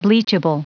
Prononciation du mot bleachable en anglais (fichier audio)
Prononciation du mot : bleachable